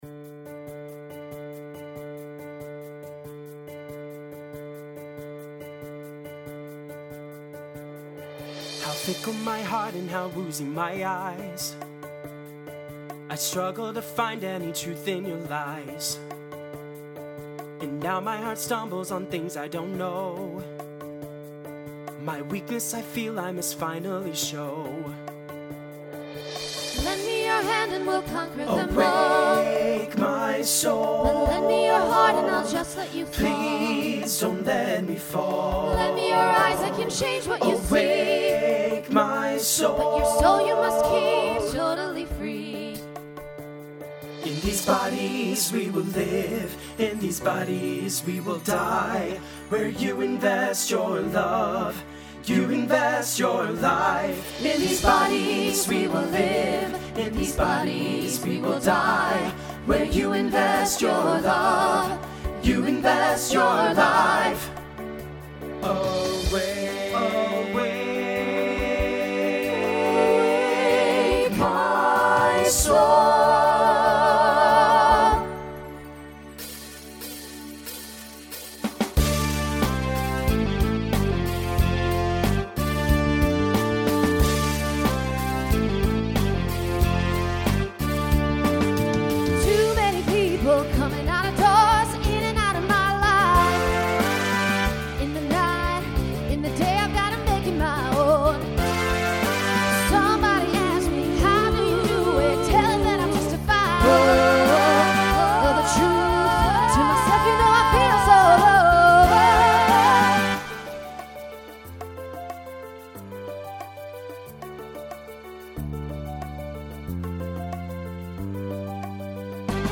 Voicing Mixed Instrumental combo Genre Rock